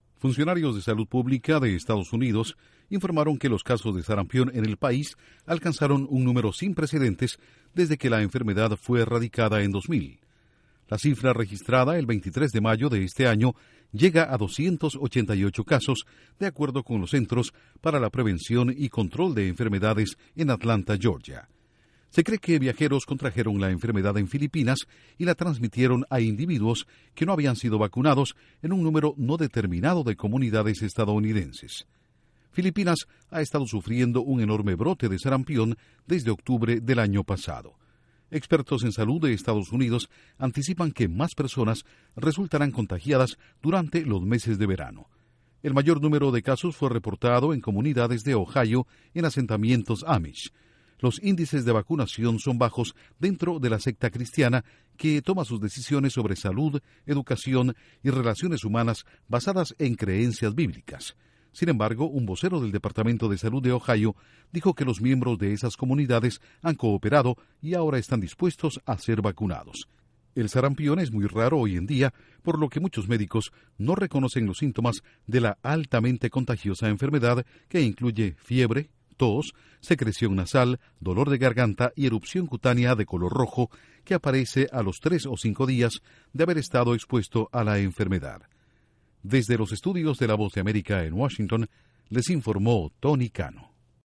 Intro: Funcionarios de salud en Estados Unidos revelaron un aumento de los casos de sarampión en el país. Informa desde los estudios de la Voz de América en Washington